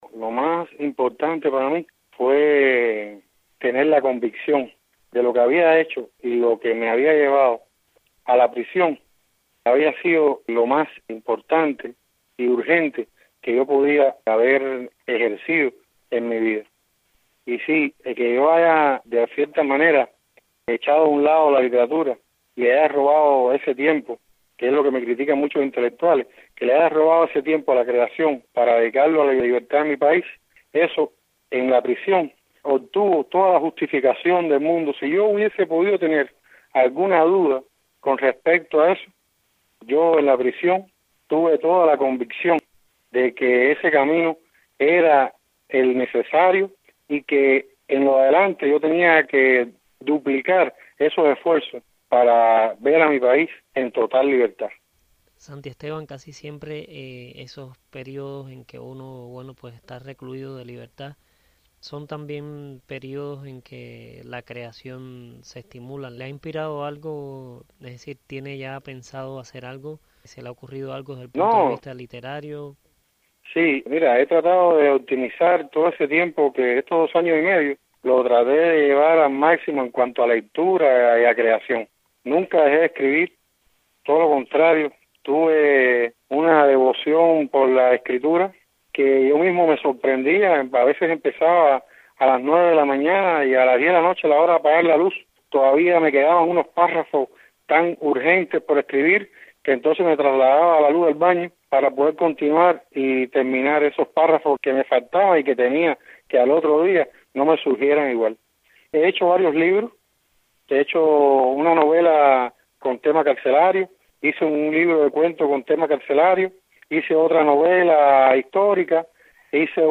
Declaraciones del escritor